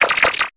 Cri d'Araqua dans Pokémon Épée et Bouclier.